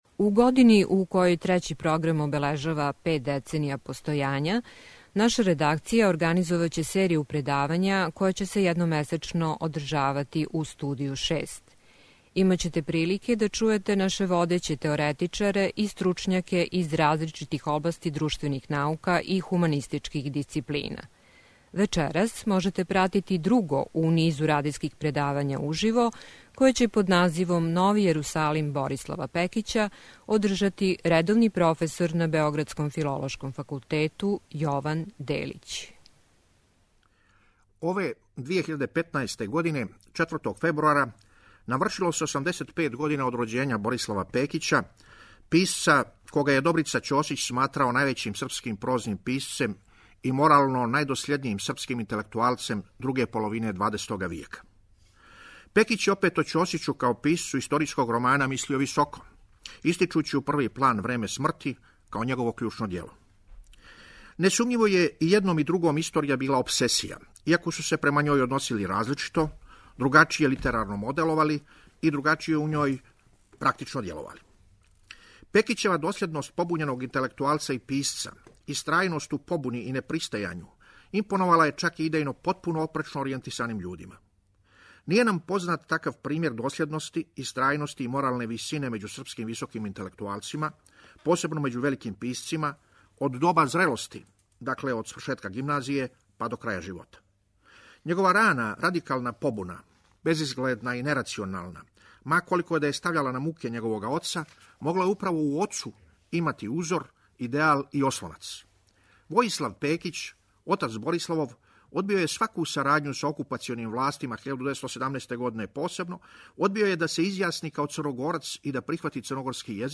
Радијско предавање у Студију 6